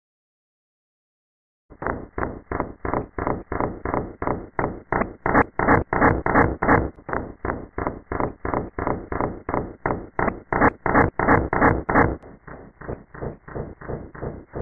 BirDepreminSesi.wma